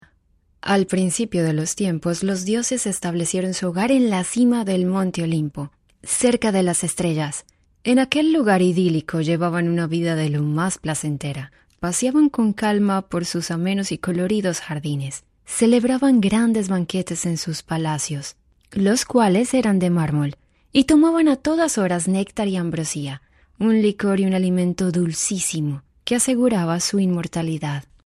Audiolibros
Voz versátil y expresiva, hablante nativa de español.
Tono neutro latinoamericano y acentos colombianos.
Tono: Medio (Natural) y Alto-Bajo Opcional.
Acentos: Español colombiano nativo y español neutro LATAM.